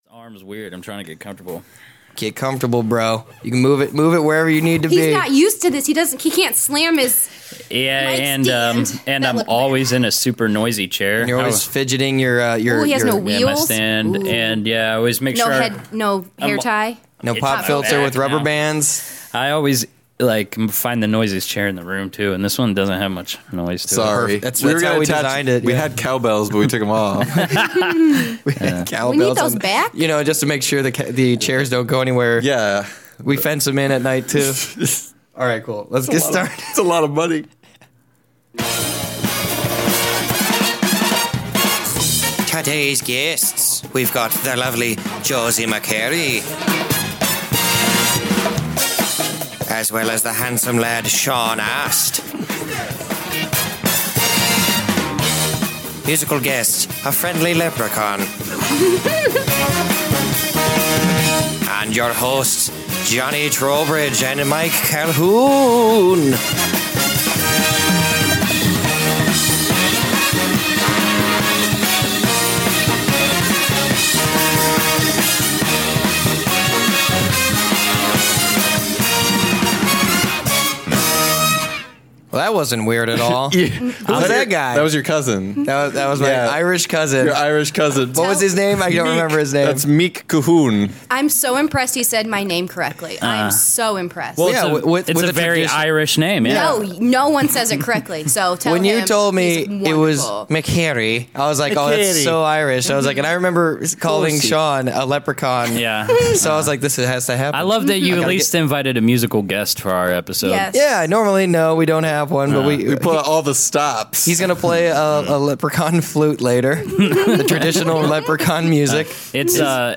A couple of Irish podcasters come aboard the sub and tell us about the biz.